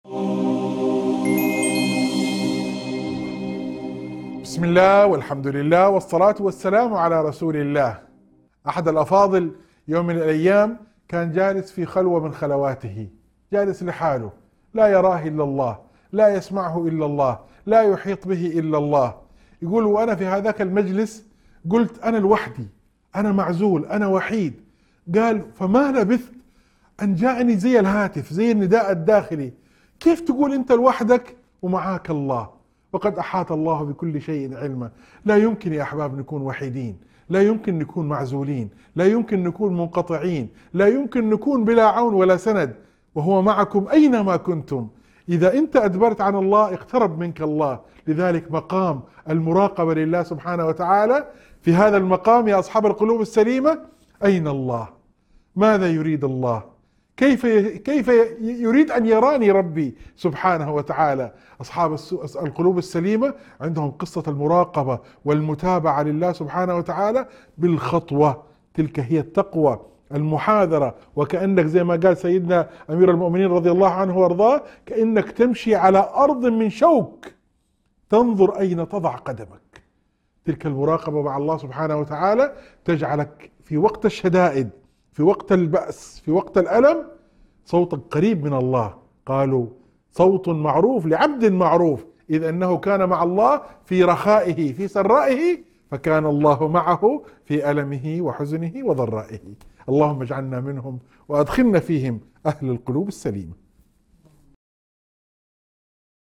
موعظة مؤثرة عن معية الله تعالى للإنسان وعدم وحدته أبداً ما دام مع ربه. يتناول النص مقام المراقبة لله وكيف أن القرب منه يجلب السند والأنس في السراء والضراء، ويحث على تزكية القلب والسير على طريق التقوى.